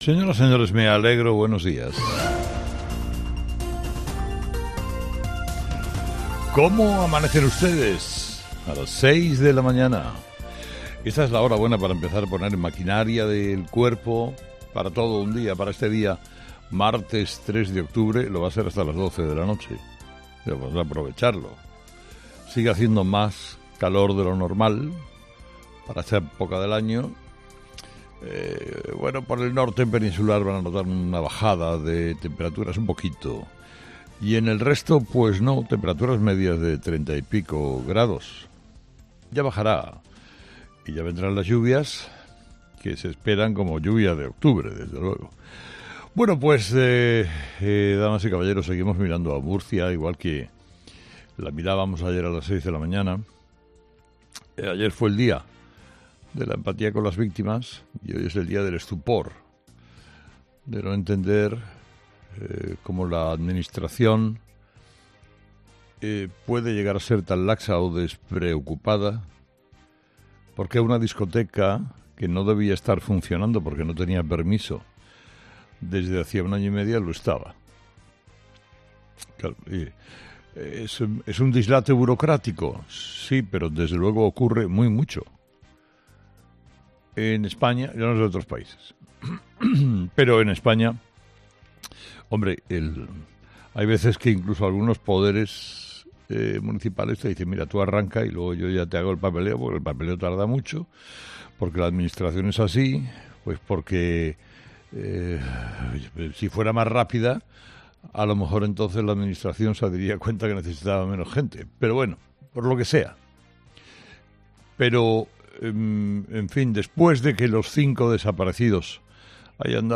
Carlos Herrera repasa los principales titulares que marcarán la actualidad de este martes 3 de octubre